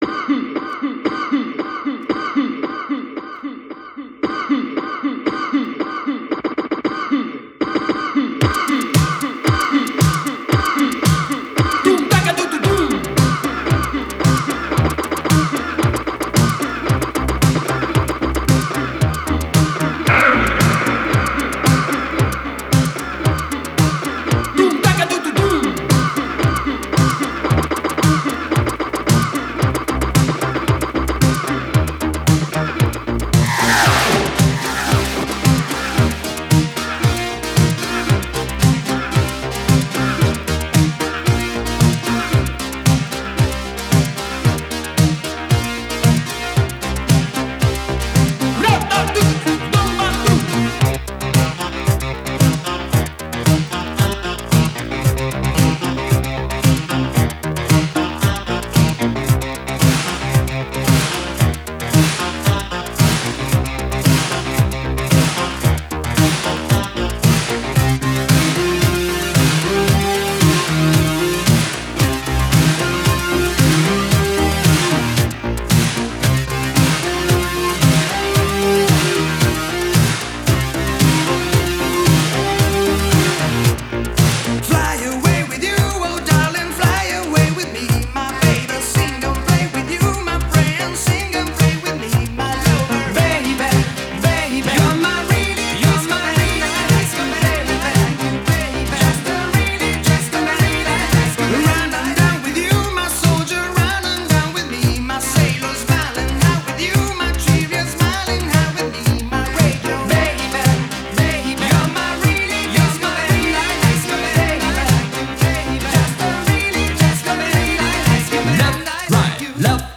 Genre: Electronic
Style: Italo-Disco
Keyboards, Guitar
Lead Vocals, Backing Vocals